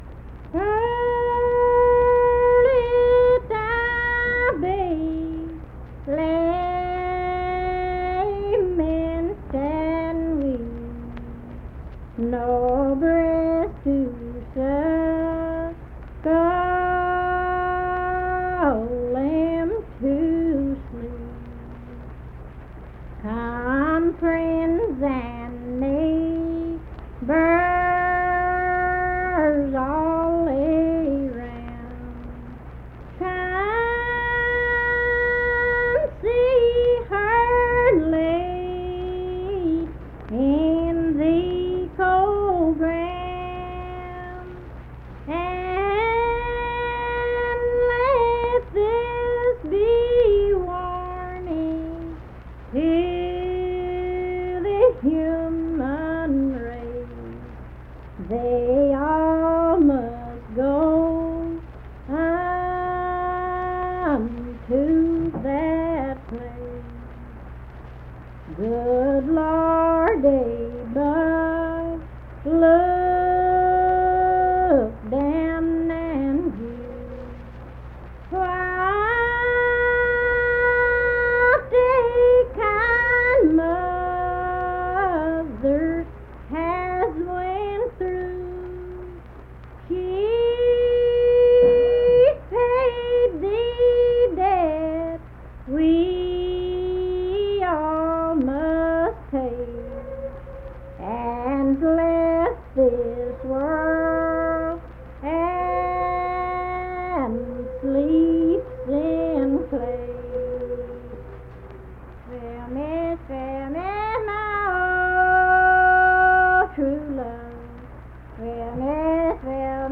Unaccompanied vocal music
Voice (sung)
Lincoln County (W. Va.), Harts (W. Va.)